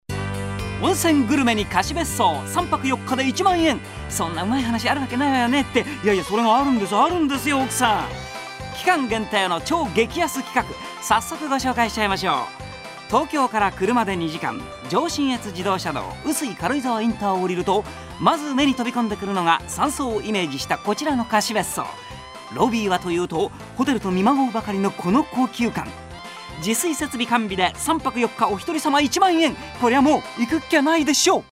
LA VOZ DE THANATOS: Toshio Furukawa
la descarga del DEMO de Furukawa-san.
sample-voice-toshio.mp3